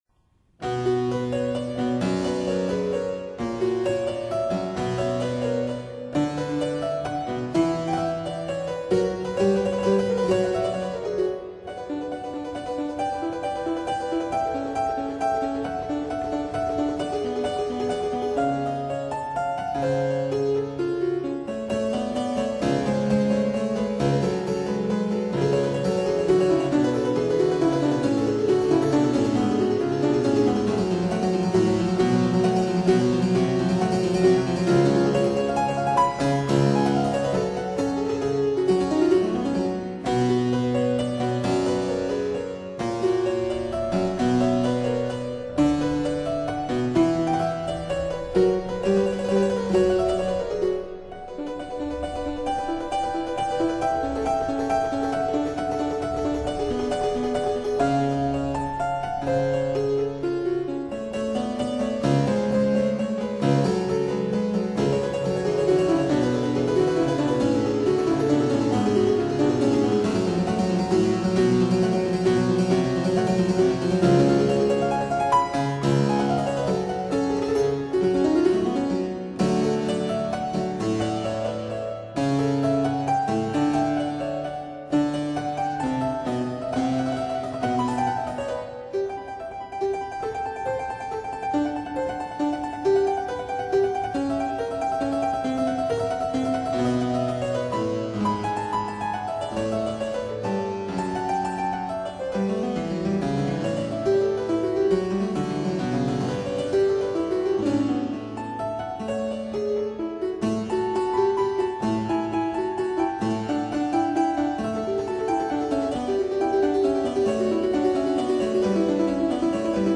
Allegro [Presto]    [0:05:17.65]